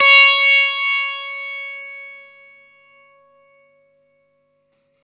R12NOTE CS+2.wav